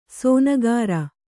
♪ sōnagāra